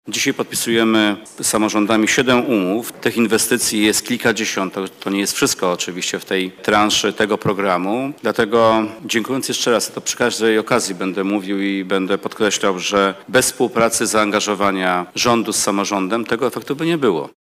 – Kolejne inwestycje są możliwe dzięki dobrej współpracy rządu i władz lokalnych – mówi wojewoda mazowiecki, Zdzisław Sipiera.